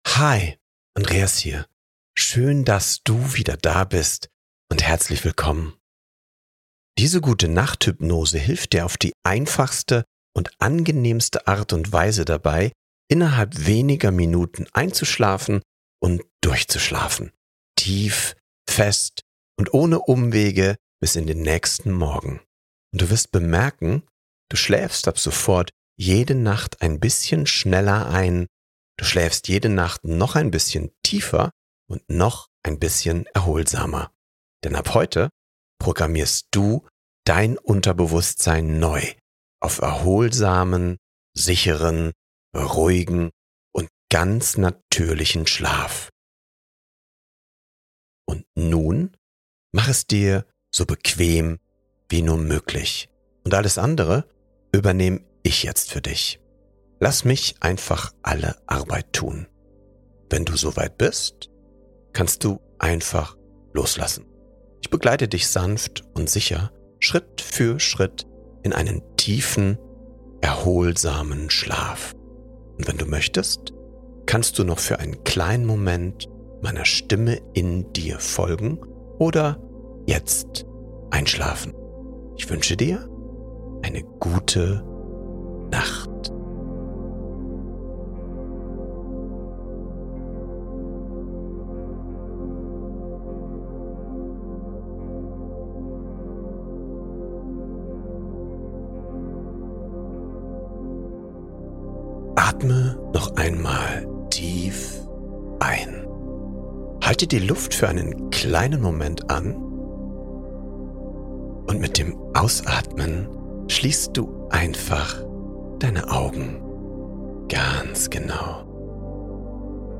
ENDLICH DURCHSCHLAFEN | Gute Nacht Hypnose (extra stark!) ~ Happiness Mindset Podcast